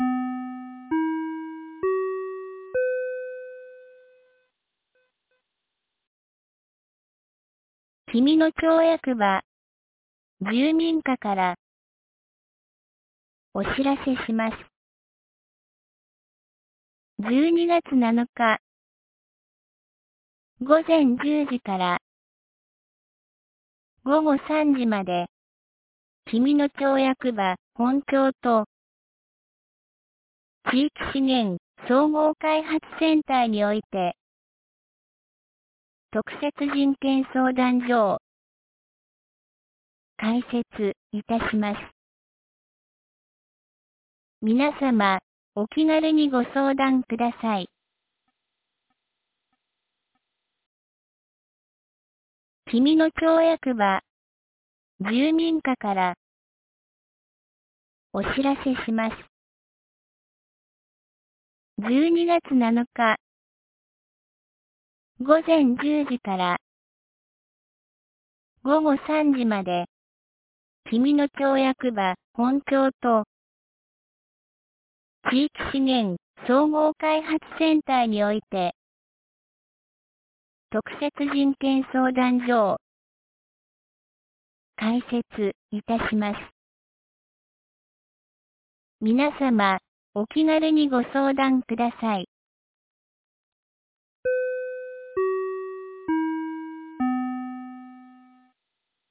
2023年12月06日 17時06分に、紀美野町より全地区へ放送がありました。